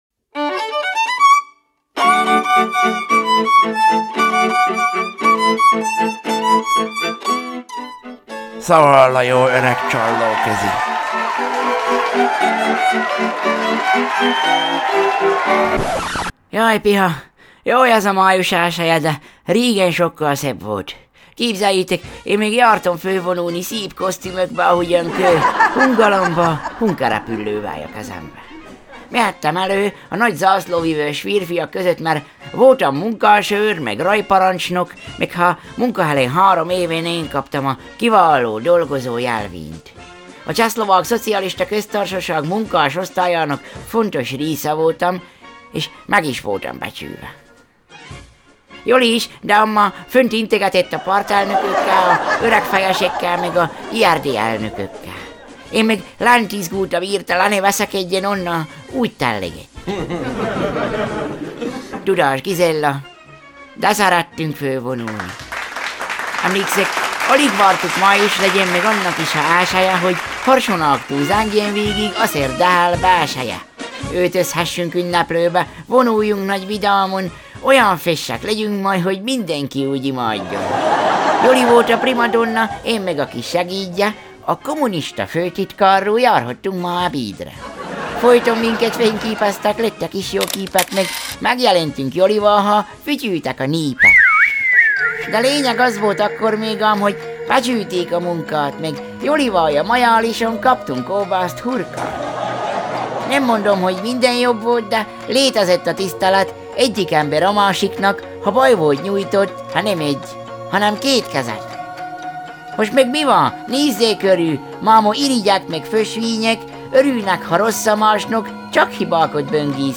Zene: